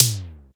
MB Perc (4).wav